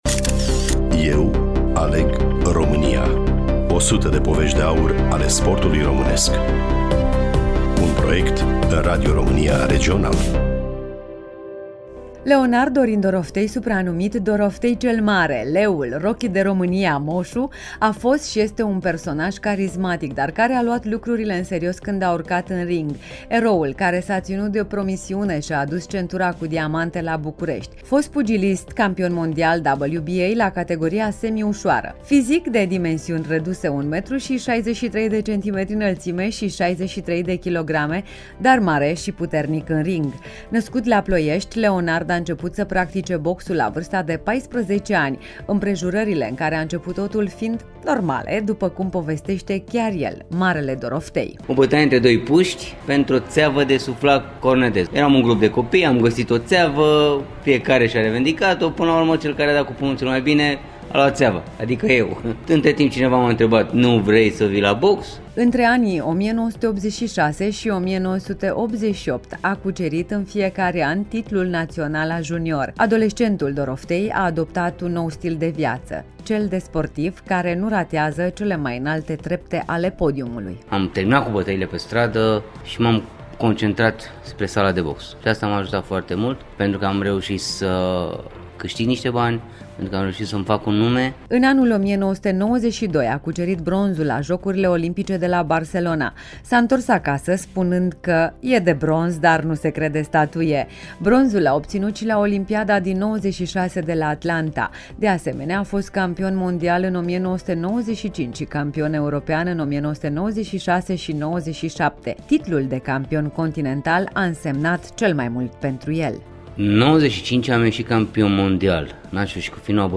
Studioul Radio România Reşiţa